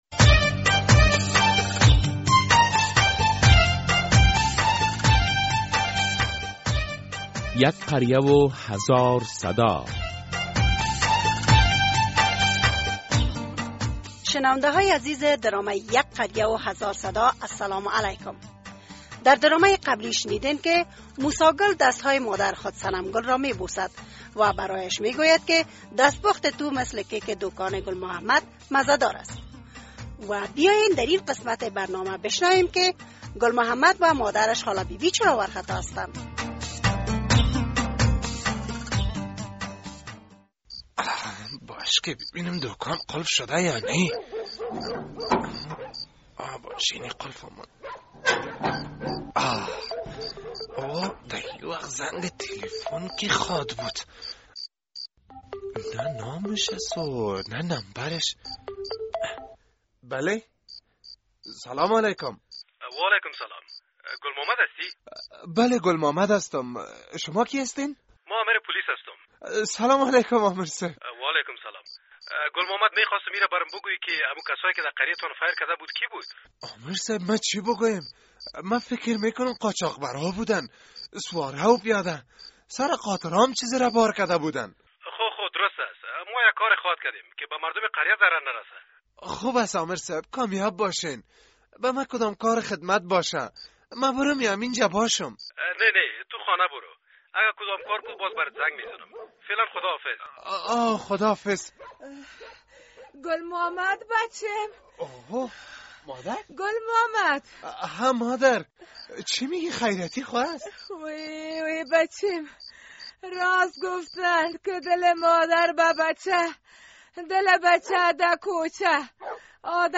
درامه یک قریه هزار صدا قسمت ۲۱۴